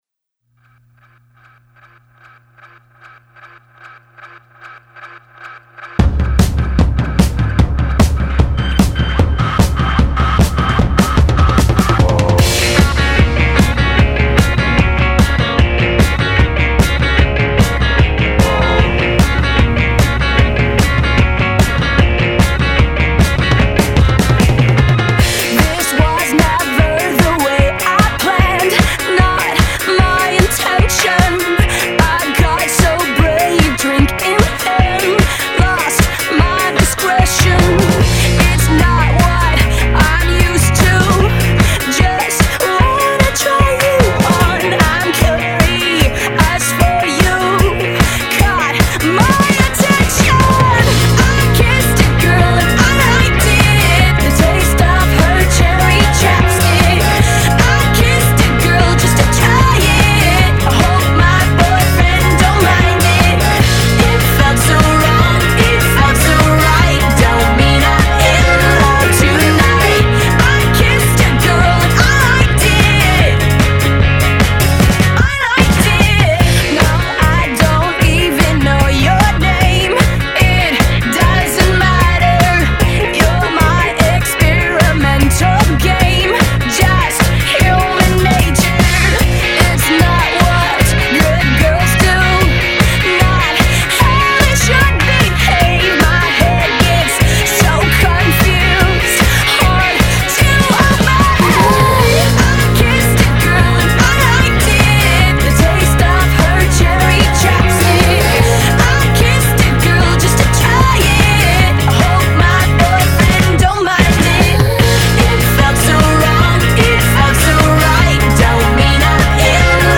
mash-up